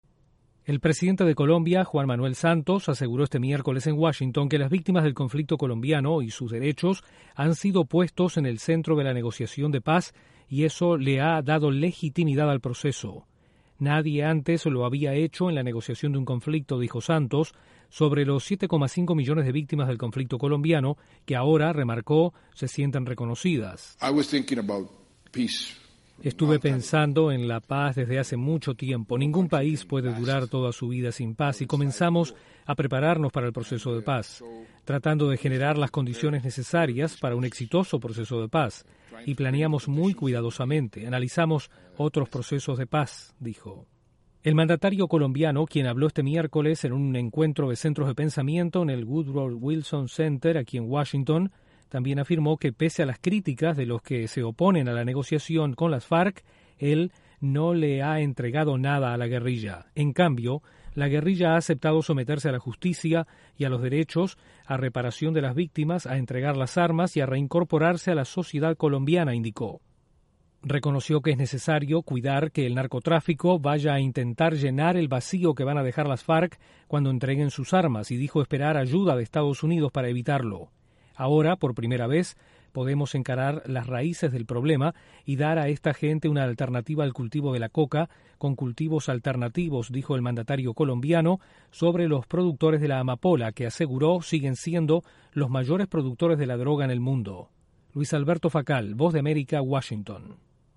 El presidente de Colombia, Juan Manuel Santos, de visita en EE.UU. defendió el proceso de paz con las FRAC. Desde la Voz de América en Washington informa